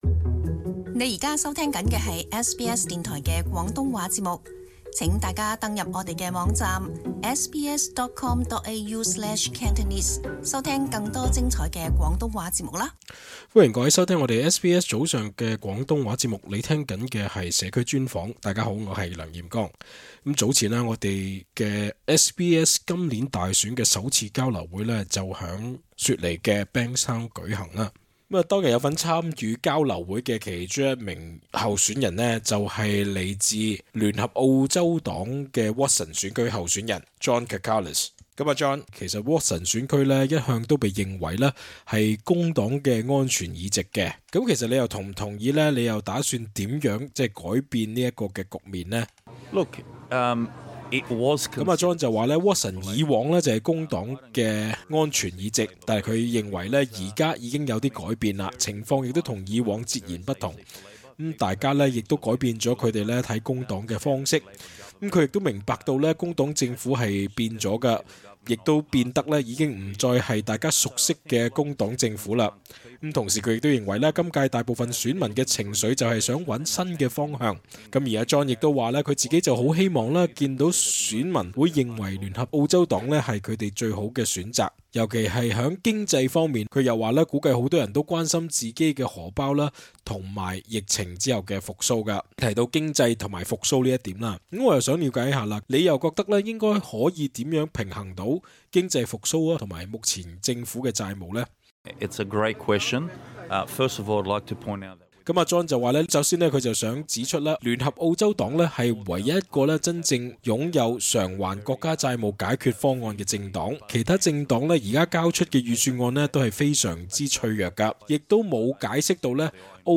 SBS早前於雪梨Bankstown舉行了今屆大選的首場交流會，多名政黨候選人及華文社區領袖當日亦到場與我們語言組節目的同事及到場的聽眾朋友交流意見。
cantonese_comm_interview_0105_bankstown_final_podcast_mp3.mp3